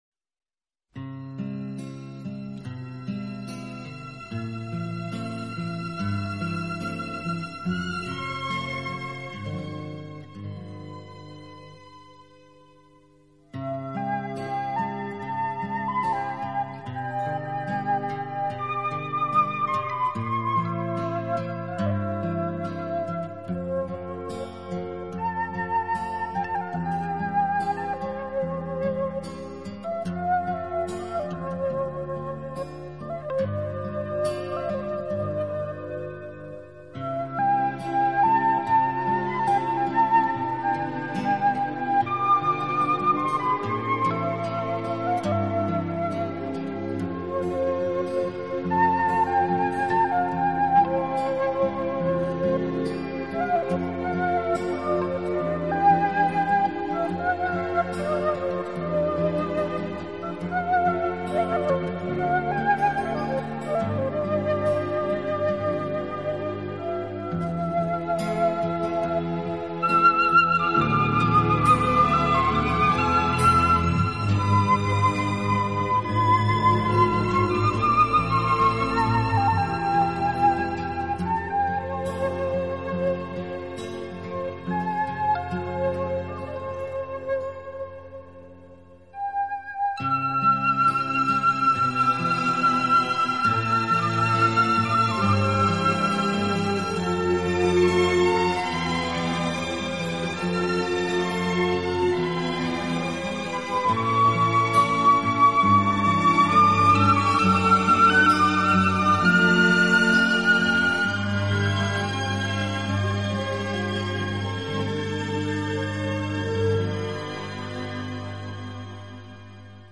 笛子都是主要乐器。
的音乐总是给人那幺大气、庄严和堂皇的感觉。